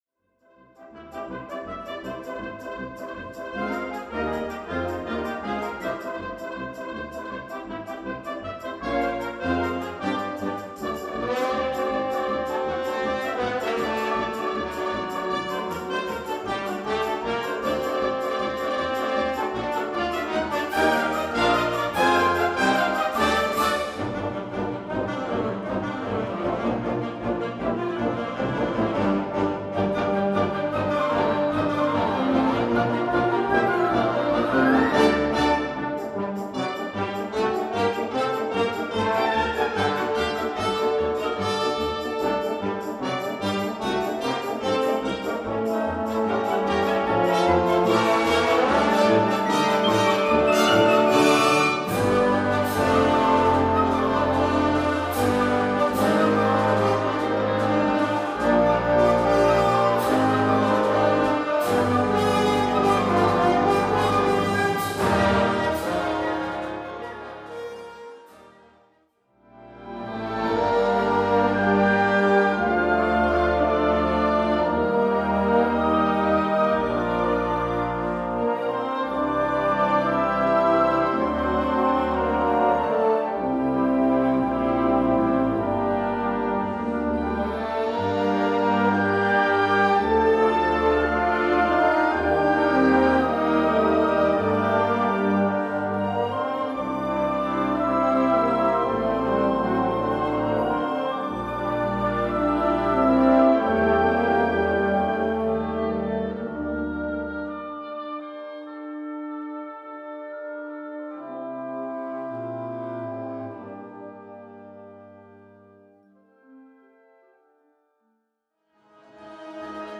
Catégorie Harmonie/Fanfare/Brass-band
Sous-catégorie Musique contemporaine (1945-présent)
Instrumentation Ha (orchestre d'harmonie)